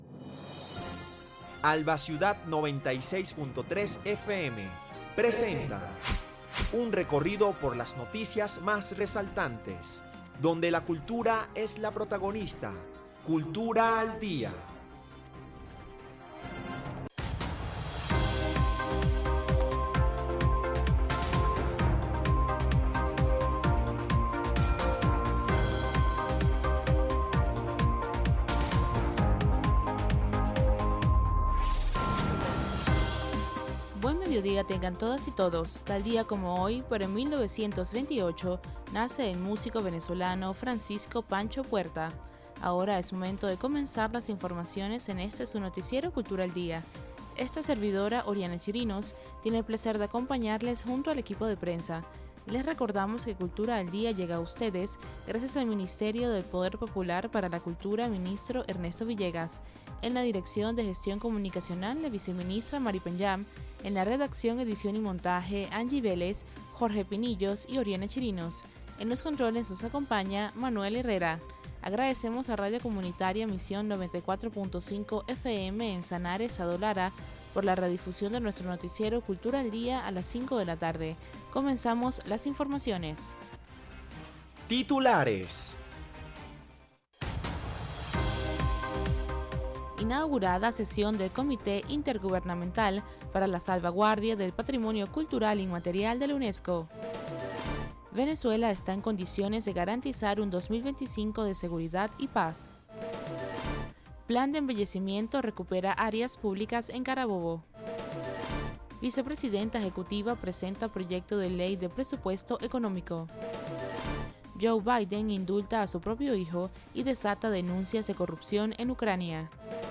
Noticiero de Alba Ciudad. Recorrido por las noticias más resaltantes del acontecer nacional e internacional, dando prioridad al ámbito cultural.